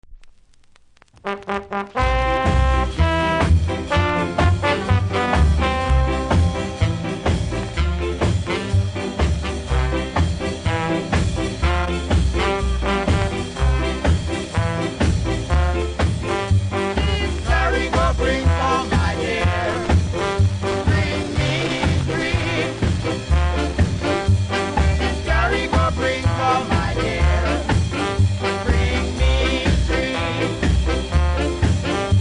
20秒ぐらいにザザーとノイズありますので試聴で確認下さい。